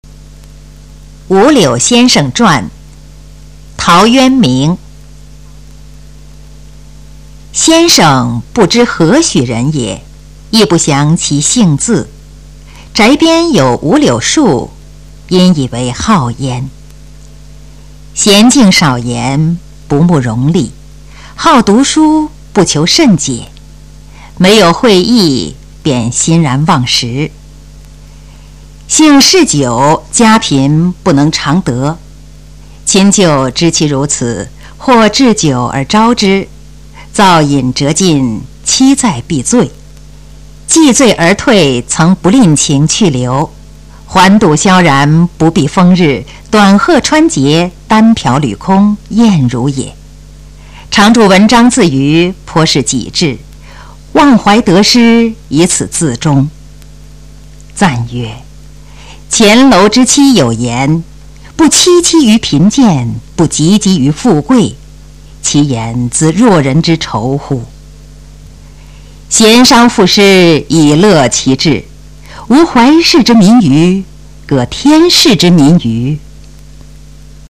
首页 视听 语文教材文言诗文翻译与朗诵 初中语文八年级下册
《五柳先生传》原文和译文（含赏析、朗读）　/ 陶渊明